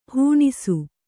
♪ hūṇisu